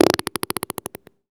fart_squirt_15.WAV